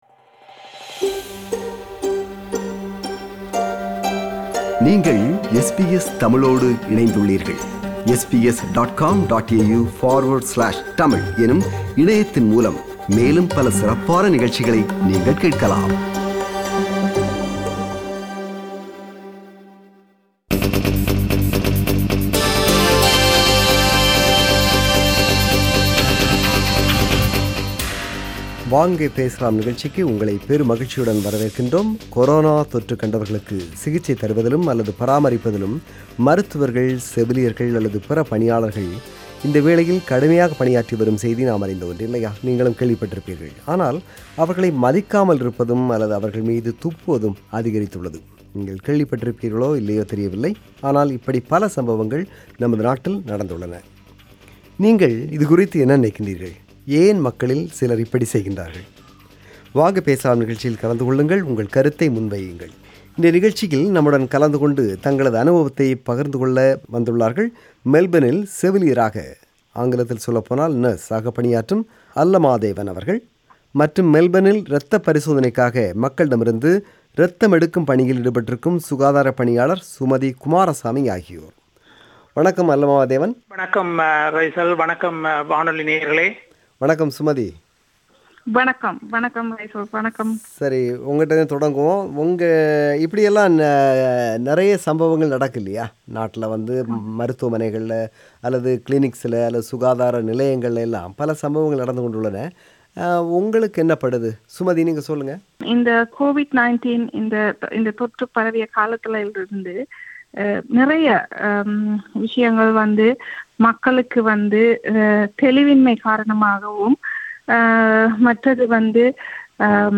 There are numerous incidents reported where some people assaulted, attacked, and coughing and spitting healthcare workers. These are comments shared by our listeners who participated in “Vanga Pesalam” program on Sunday (26 April).